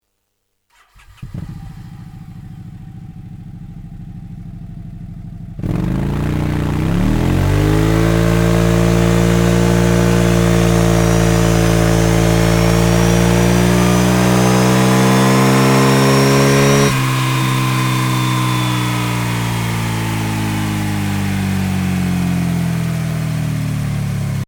Mit dem Akrapovic Slip-On System bekommt Ihr Scooter diesen unverkennbaren tiefen und satten Akrapovic Sound, und auch das optische Erscheinungsbild ändert sich dramatisch.
Sound Akrapovic Slip-On